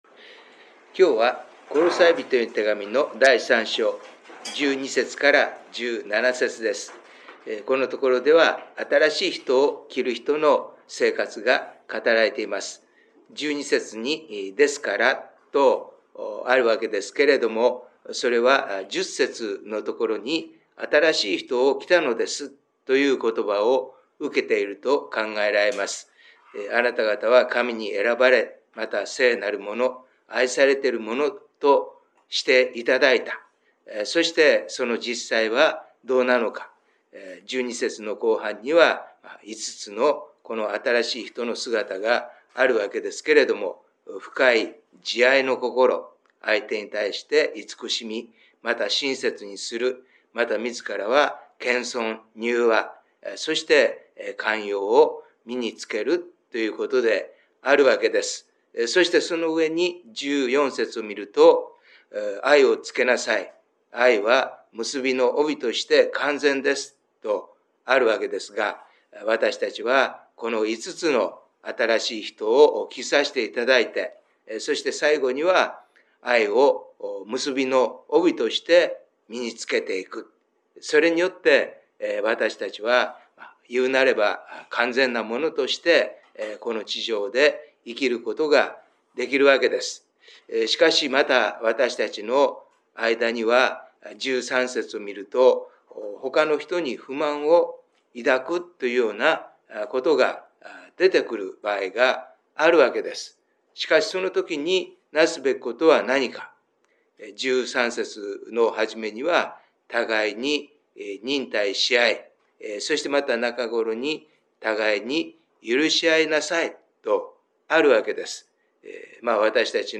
3月のデボーションメッセージ